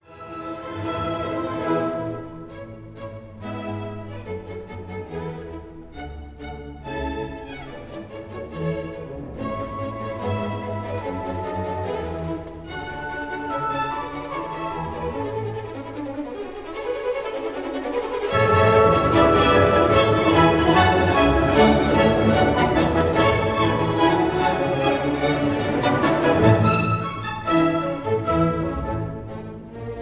conductor
Recorded in the Kingsway Hall, London